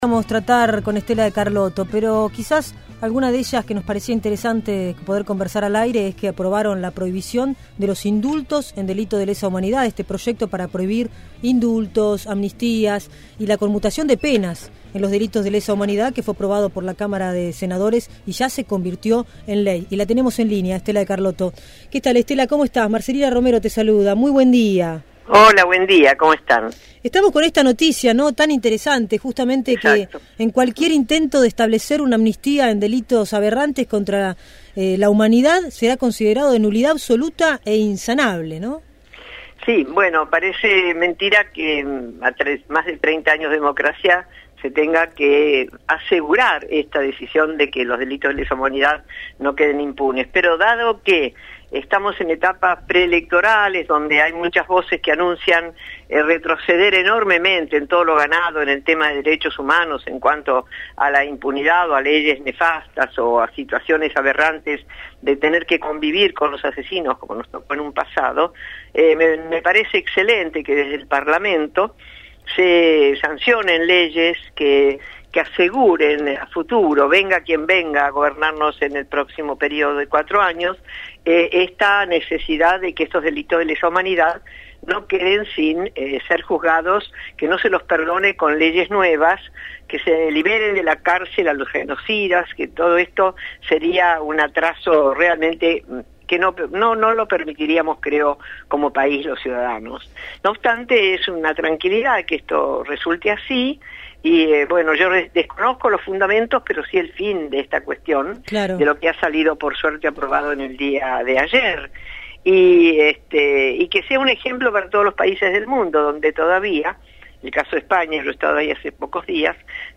Estela de Carlotto, presidenta de Abuelas de Plaza de Mayo, en diálogo